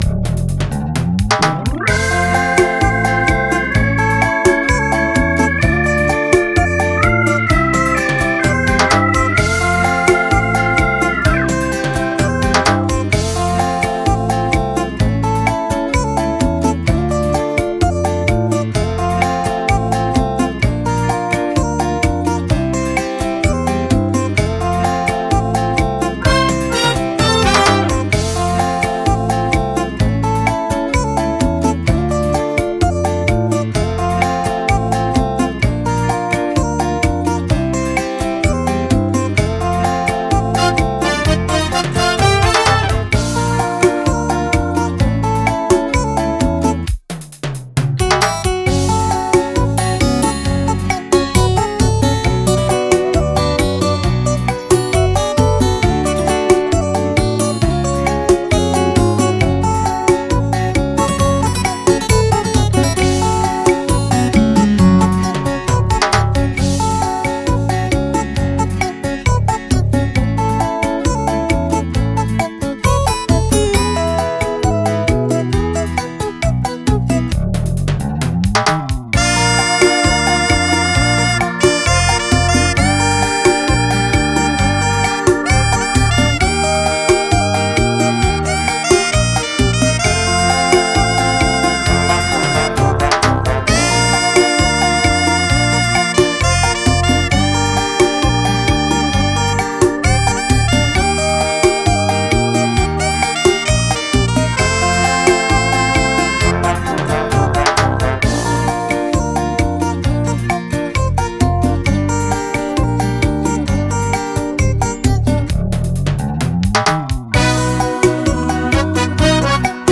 Bregão em Seresta